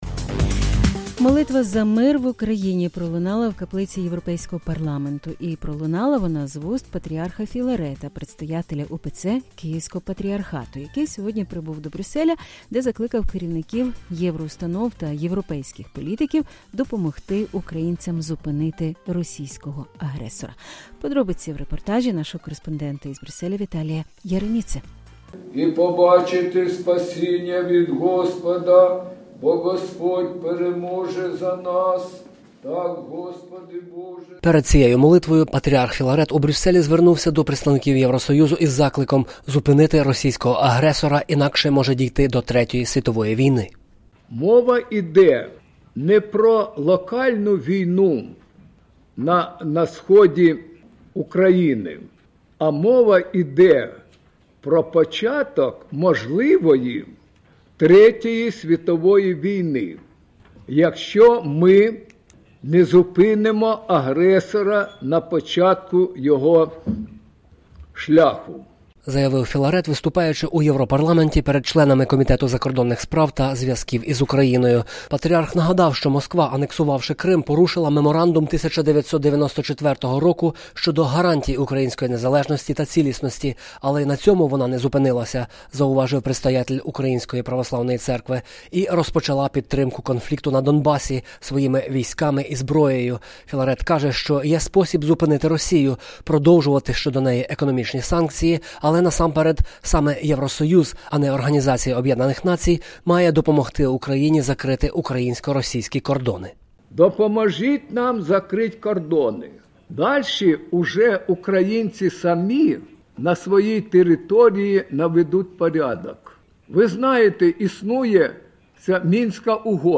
Брюссель – Молитва за мир в Україні пролунала у каплиці Європейського парламенту.
«Мова йде не про локальну війну на сході України, а мова йде про початок можливої Третьої світової війни. І конфлікт в Україні перетвориться у неї, якщо ми не зупинимо агресора на початку його шляху», – заявив Філарет, виступаючи у Європарламенті перед членами Комітету закордонних справ та зв’язків з Україною.